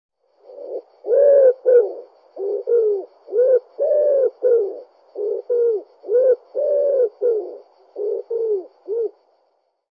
Ringeltaube - Jagd - Heintges eLearning System
Ringeltaube / Gesang
Ringeltaube-Gesang.mp3